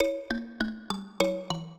mbira
minuet10-11.wav